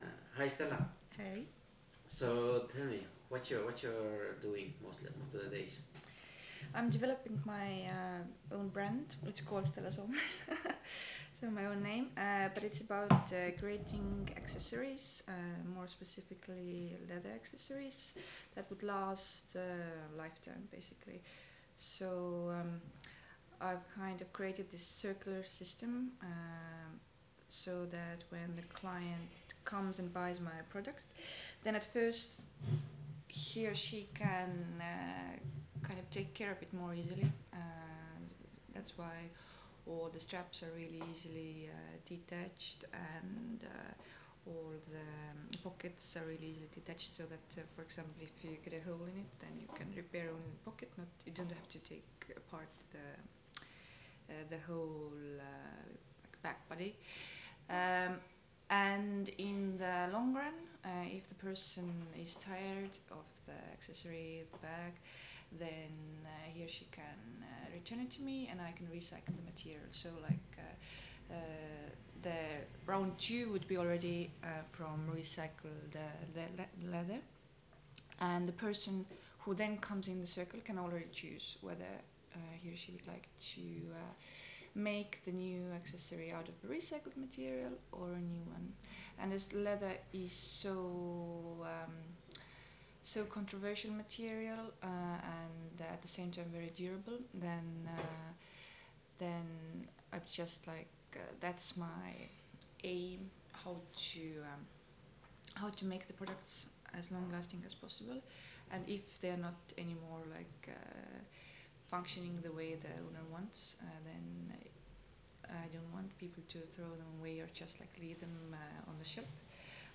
See and read more from HERE Listen interviews with PESA alumni.